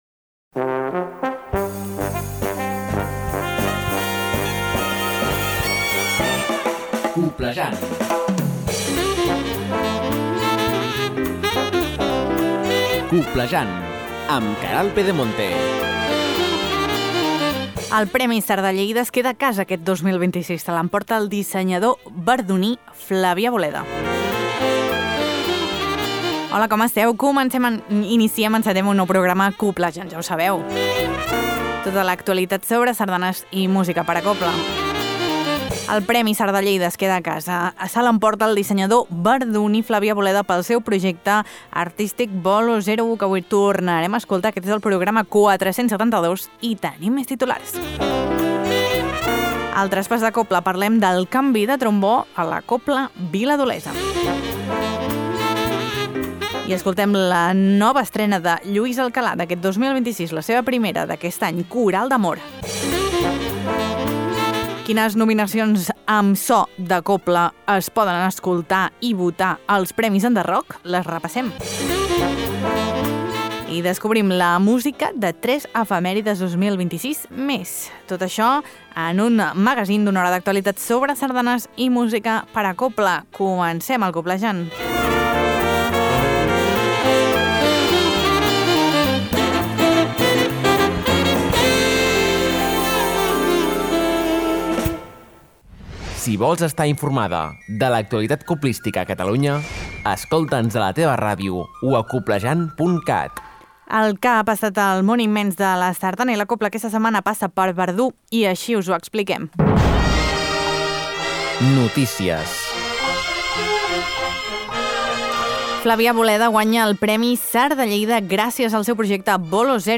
Tot això i molt més a Coblejant, un magazín de Ràdio Calella Televisió amb l’Agrupació Sardanista de Calella per a les emissores de ràdio que el vulguin i s’emet arreu dels Països Catalans. T’informa de tot allò que és notícia al món immens de la sardana i la cobla.